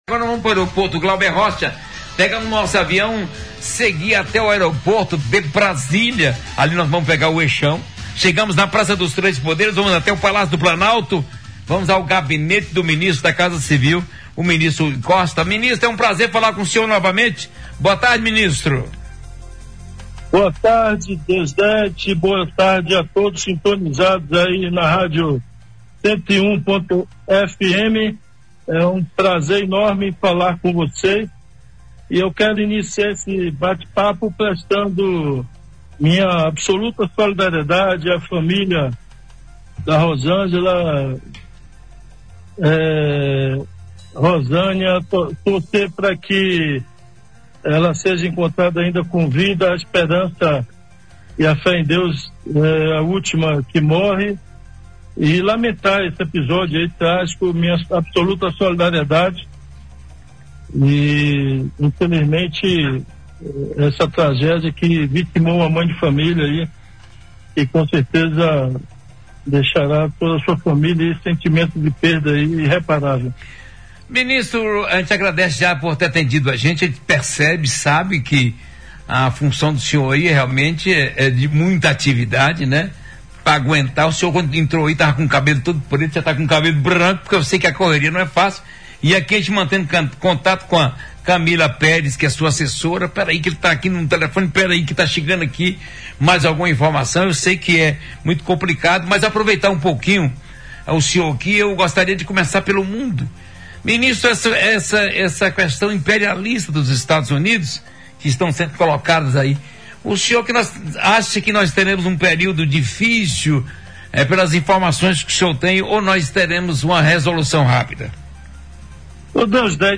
A cronologia da inscrição das obras no Programa de Aceleração ao Crescimento (PAC) e as etapas seguintes foram detalhadas pelo ministro durante entrevista ao UP Notícias, na Rádio UP, nesta terça-feira (10).